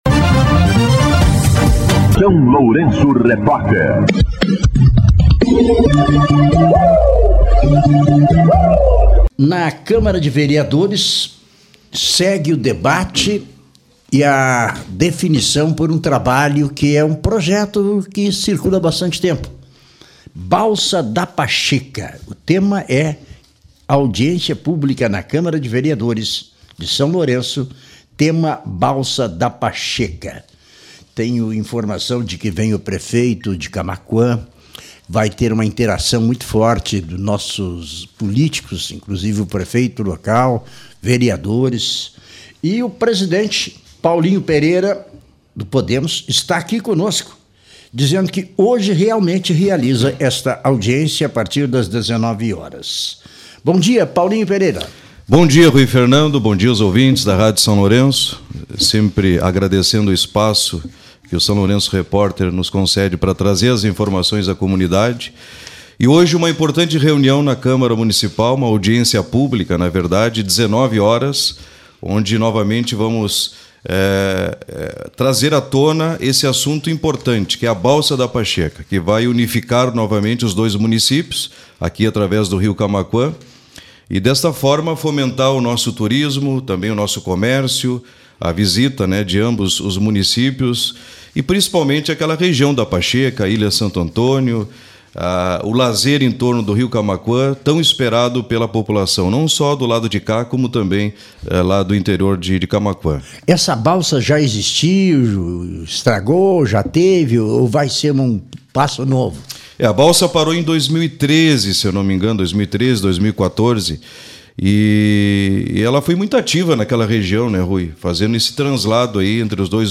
Entrevista com o vereador Paulinho Pereira (Podemos)